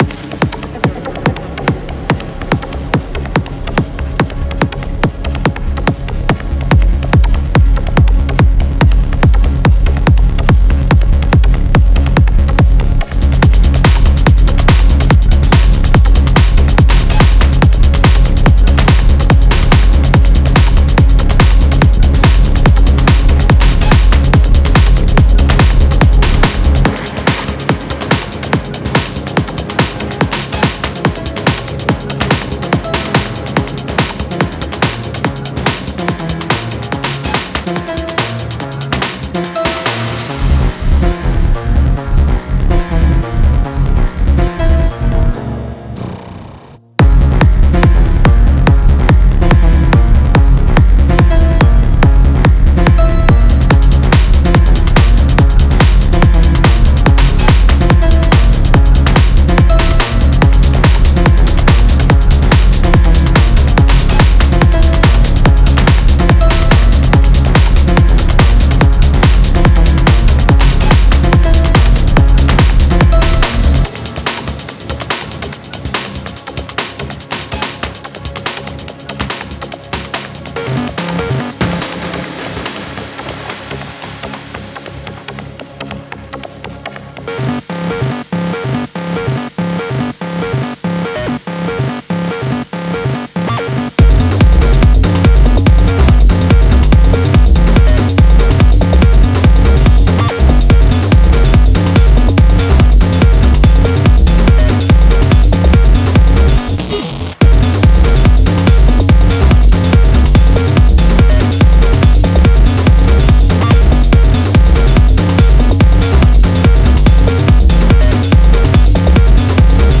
Genre:Techno
ロウでハイブリッド、そして巨大なサウンドシステムが鳴り響く暗い空間のために設計されています。
生のアナログパワーとインダストリアルな闇を遺伝子的に融合させた存在です。
叫ぶようなリード、催眠的なプラック、深みのあるコード、そして月曜の朝のアラームよりも強烈に響くベースが揃っています。
圧倒的な量のドラムループとパンチのあるドラムショットを用意し、あなたのトラックに駆動力のある機械的な魂を与えます。
デモサウンドはコチラ↓
150 Analog Synth Loops (Leads, Plucks, Basses, Chords)